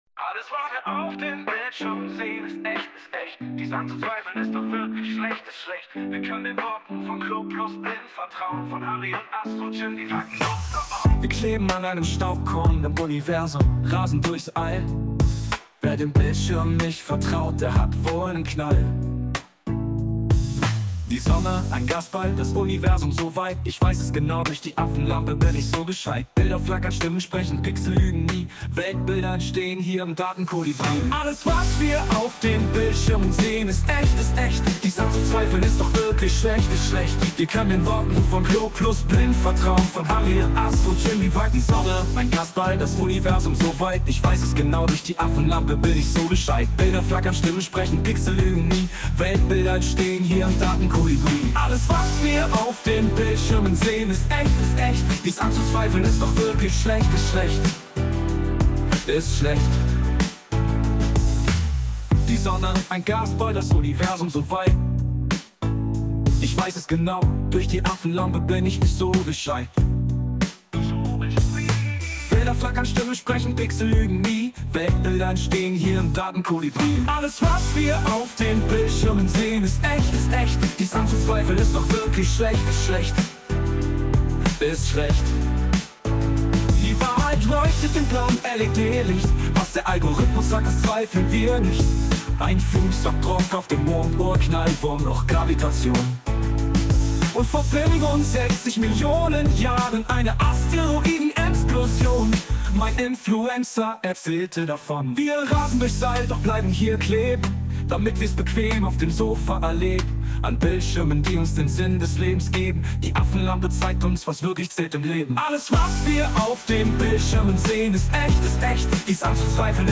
Music: Alles, was wir auf den Bildschirmen sehen, ist echt (Suno KI)
(Ton-Mix / Arrangement)